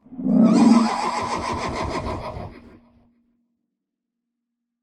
sounds / mob / horse / zombie / idle3.ogg